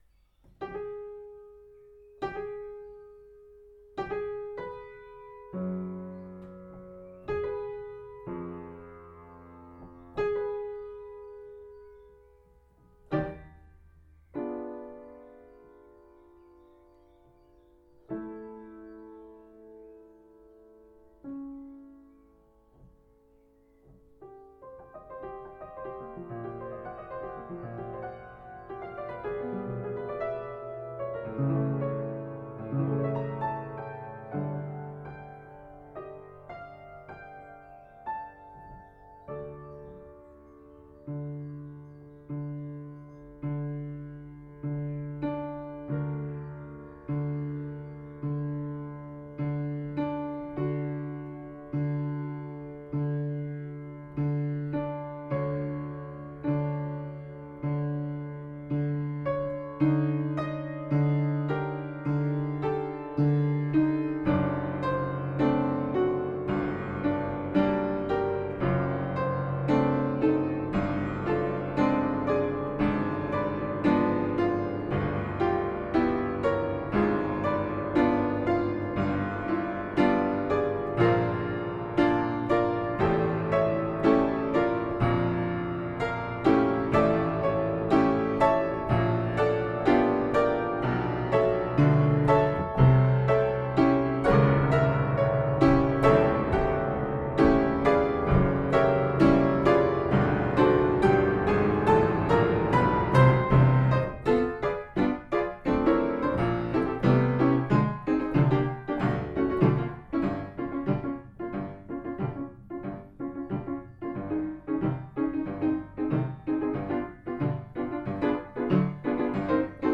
piano improvisation 20.04.2020